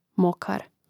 mȍkar mokar